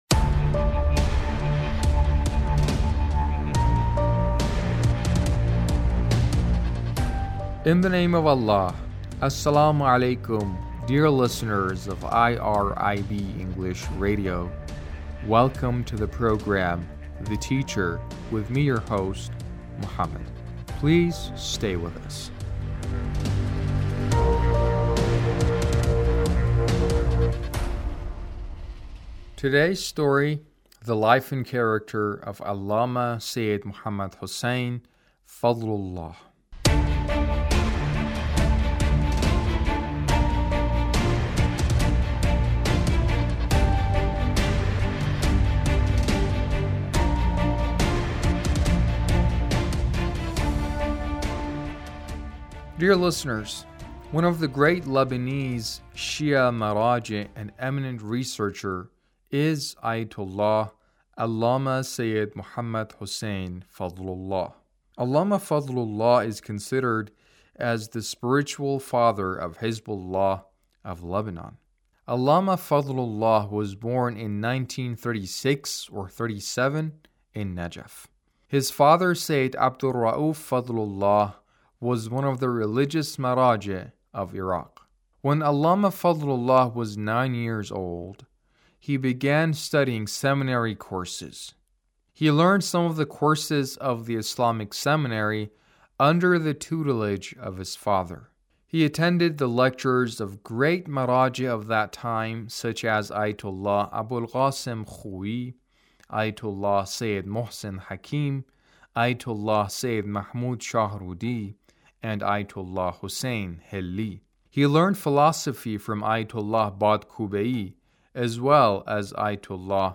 A radio documentary on the life of Allama Muhammad Hussein Fadlullah - 2